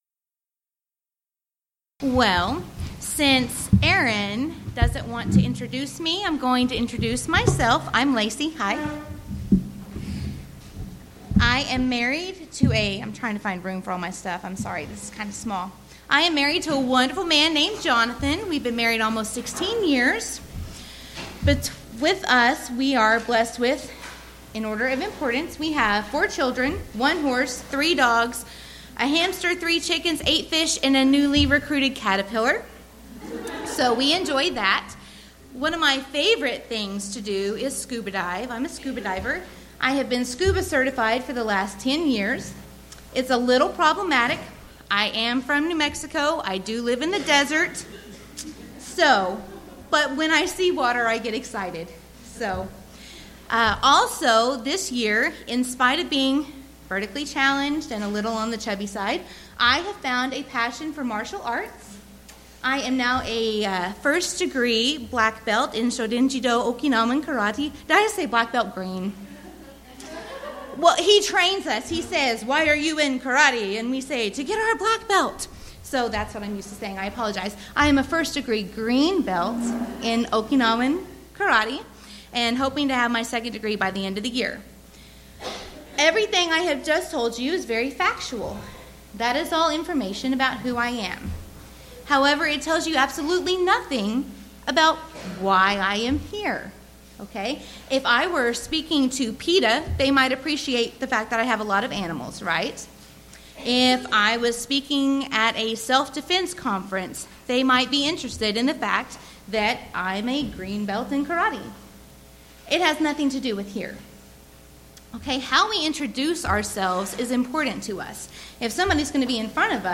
Event: 4th Annual Texas Ladies in Christ Retreat Theme/Title: Studies in 2 Peter
lecture